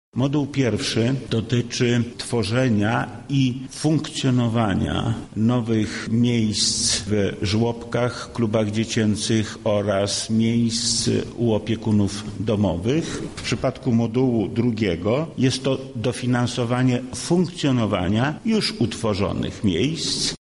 W ramach programu Maluch+ urząd wojewódzki może przekazać nawet ponad 30 tys. złotych na jedno miejsce. Trzeba tylko złożyć wniosek w jednym z czterech modułów – tłumaczy Wojewoda Lubelski Lech Sprawka: